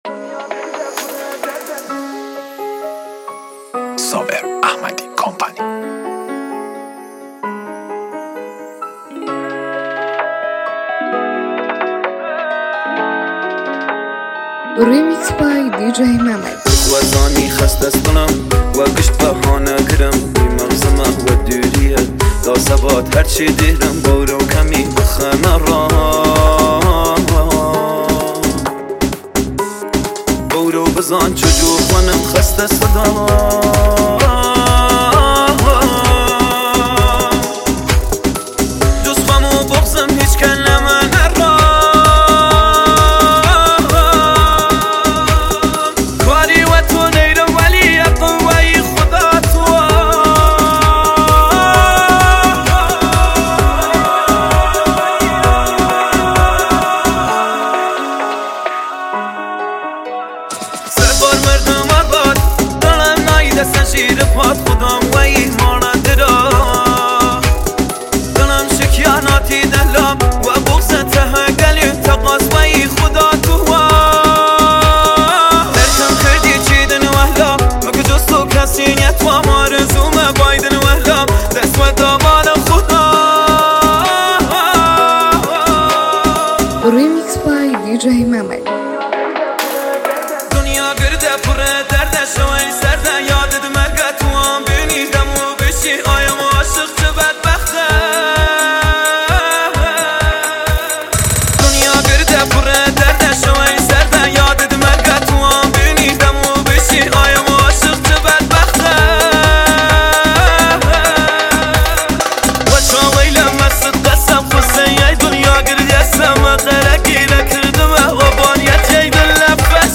ریمیکس تند بیس دار